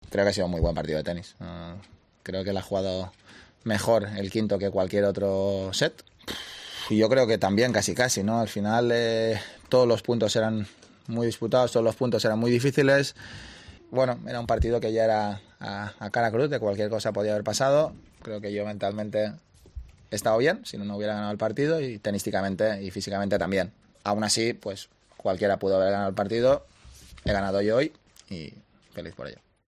"Es un momento para disfrutar y para empezar a recuperar porque ha sido una dura batalla en lo físico y el que viene ahora es uno de los rivales más complicados", aseguró el balear en la entrevista nada más acabar el partido.